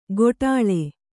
♪ goṭāḷe